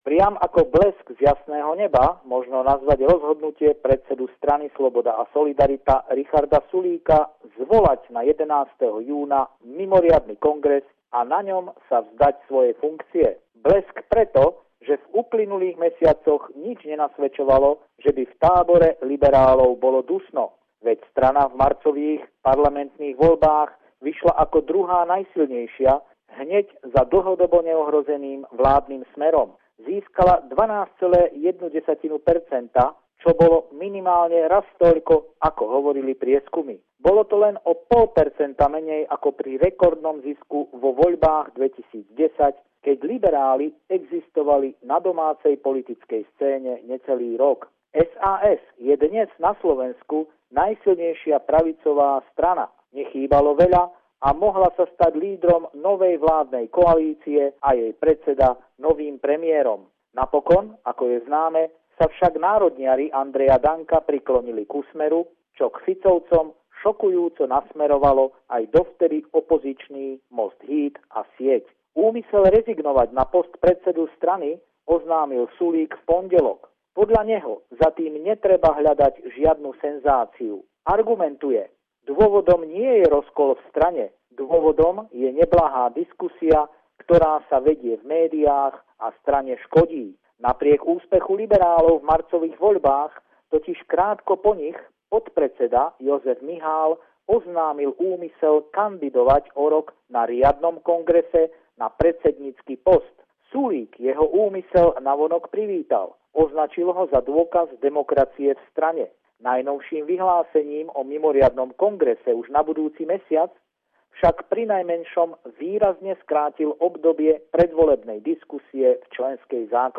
Pravidelný telefonát týždňa kolegu z Bratislavy